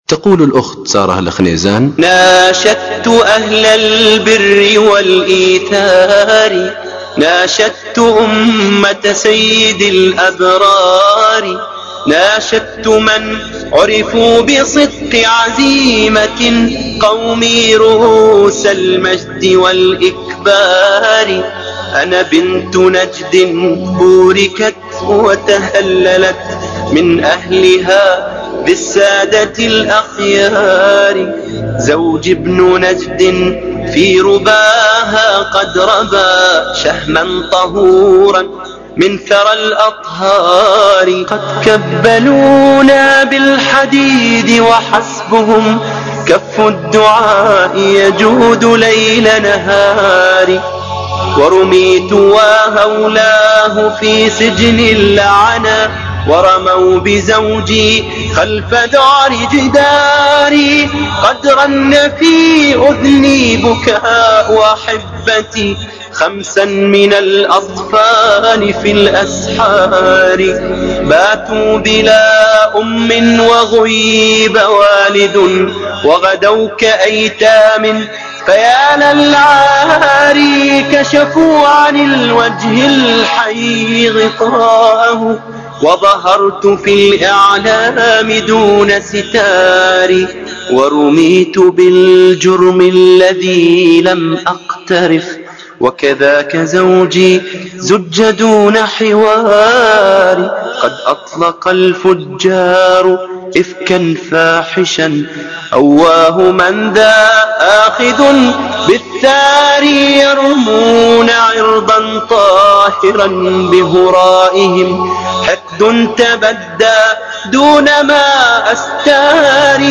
وهذا النشيد الذي طلبت